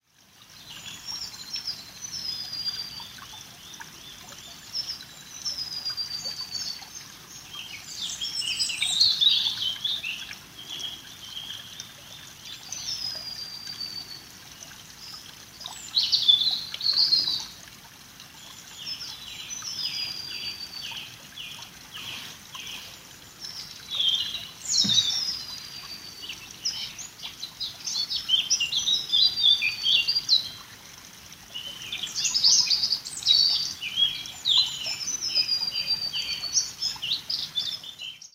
At the troll’s bridge, all we can hear is birdsong and the gently glugging of the tiny stream below – listen to the sound clip below…